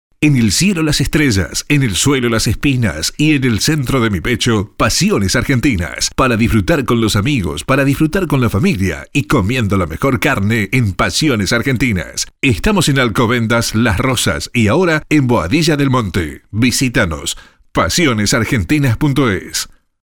Locutor.mp3